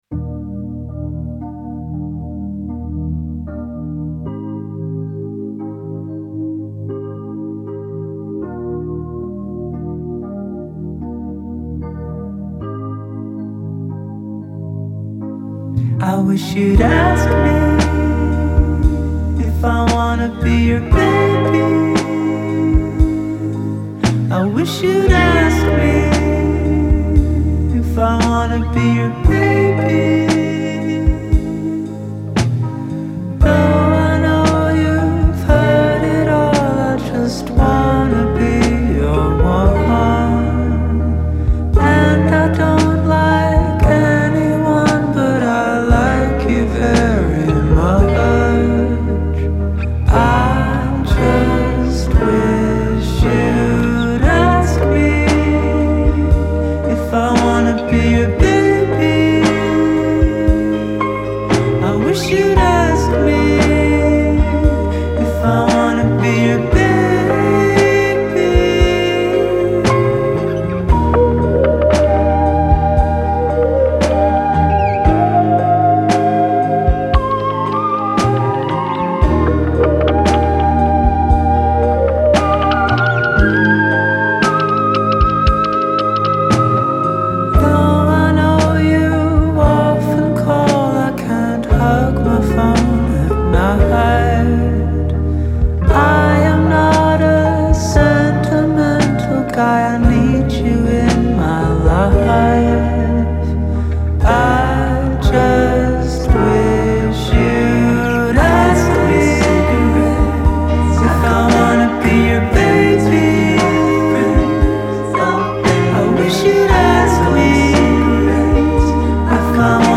Genre : Alternative, Rock